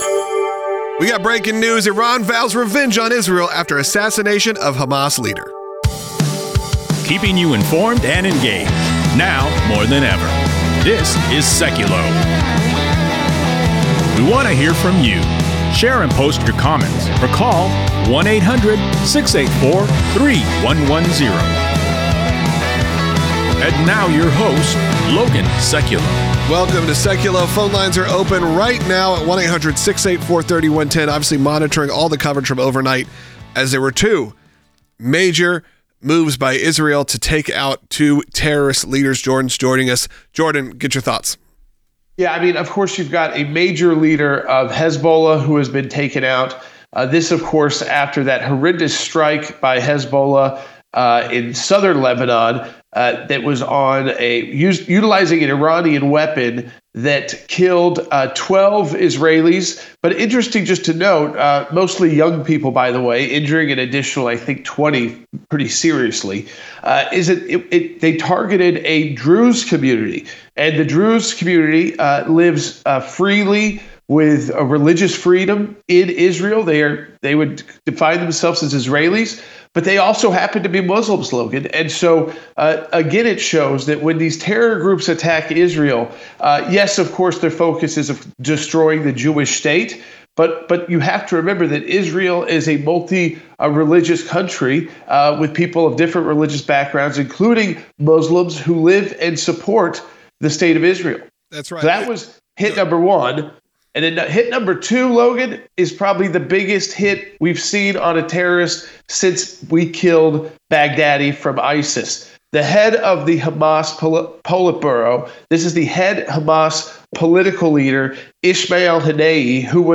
Iran Vows Revenge Following Elimination of Hamas Leader Podcast with Jay Sekulow & Jordan Sekulow
Former Secretary of State Mike Pompeo and Israeli Ambassador to the U.N. Danny Danon also join.